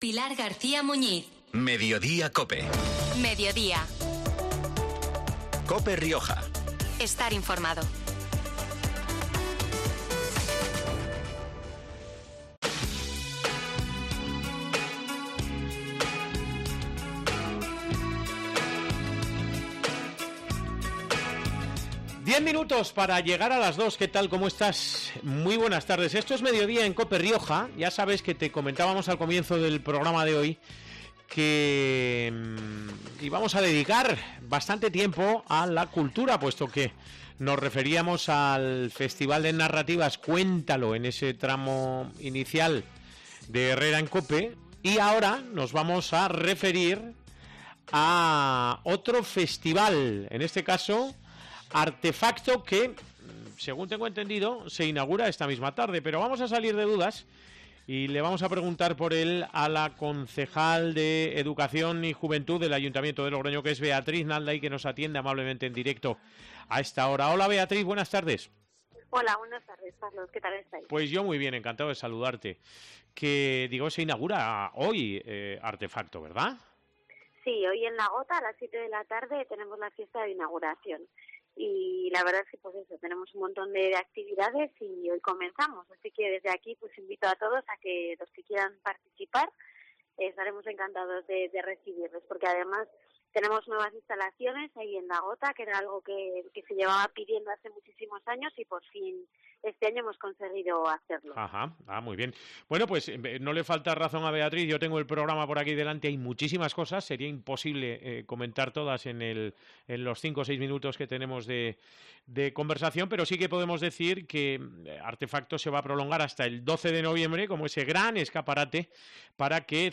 La concejal de Educación y Juventud, Beatriz Nalda, presenta en COPE el Festival de Cultura Joven 'Artefacto' 2022, que se celebra en Logroño del 3 al 12 de noviembre